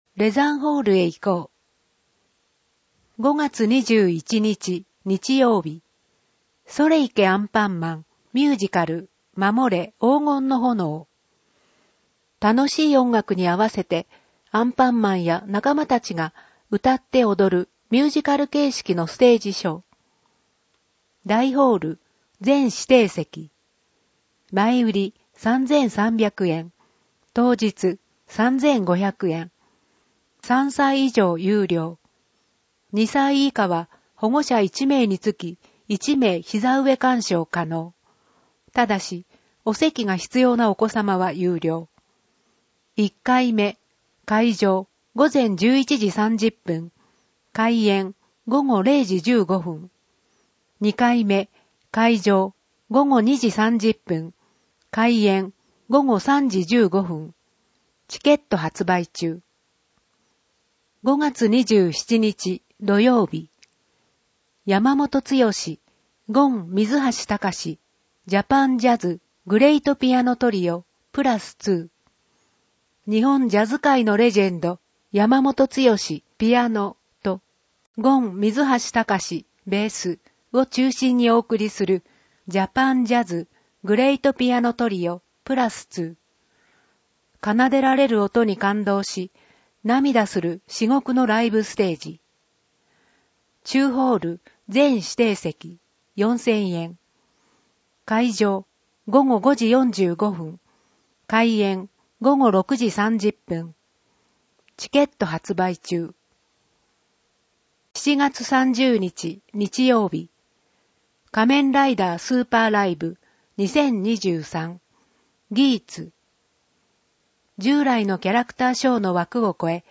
PDF版 テキスト版 声の広報